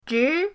zhi2.mp3